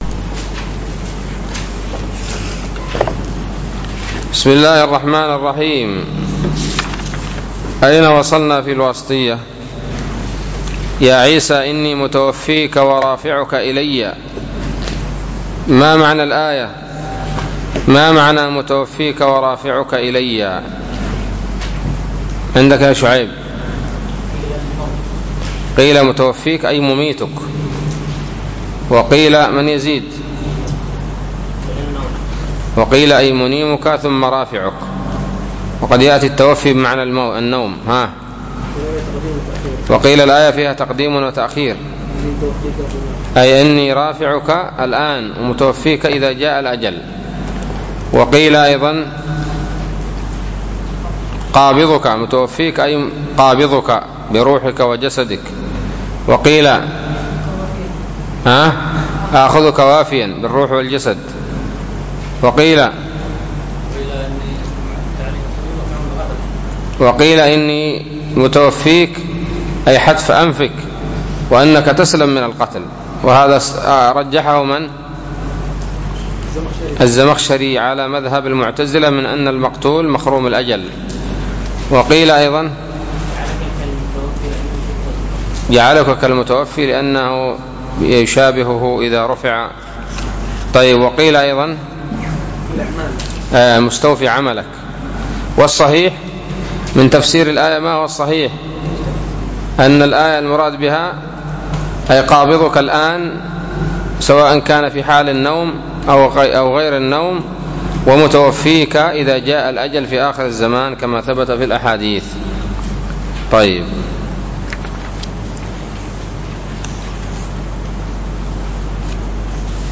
الدرس السابع والسبعون من شرح العقيدة الواسطية